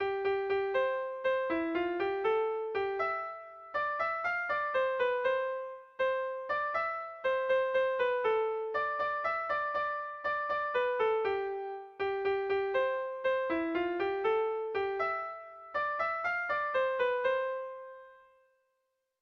ABA